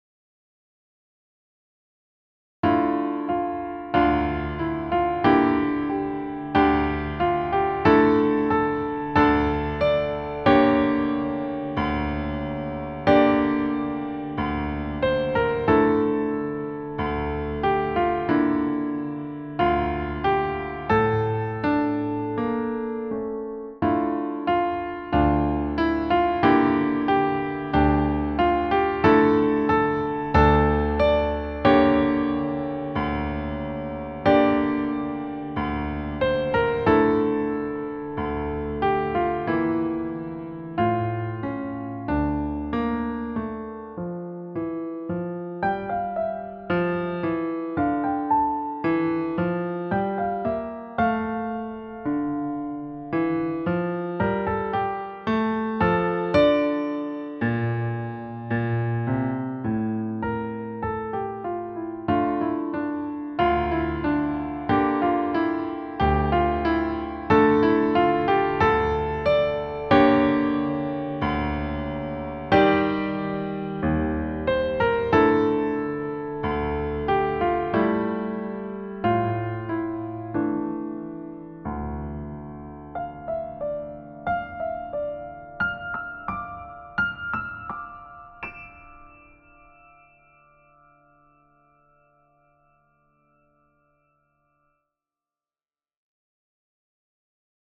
• Key: D Minor
• Time signature: 4/4
• Shaping dramatic contrasts with clear dynamic control
• Developing smoothness in minor-key phrasing